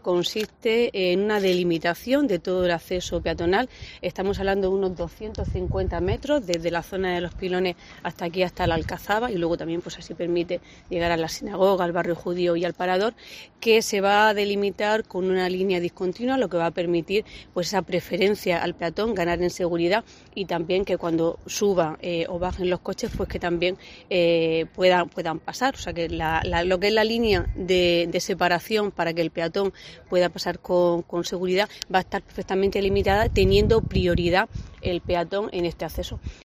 Rosa Medina, concejal del PP en Lorca